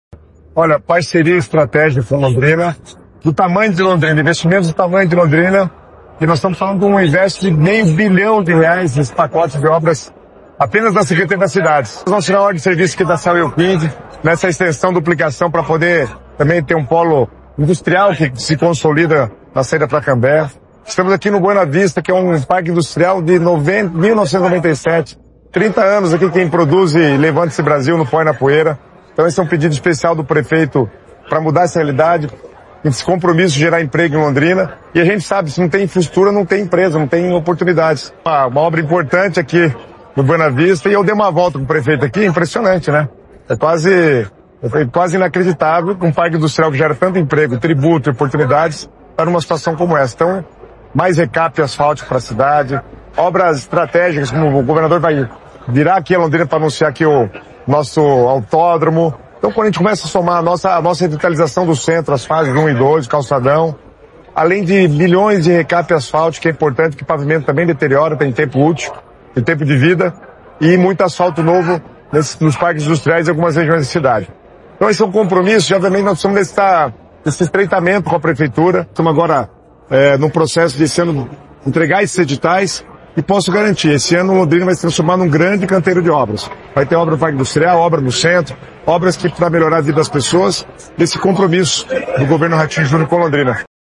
Sonora do secretário das Cidades, Guto Silva, sobre as obras em Londrina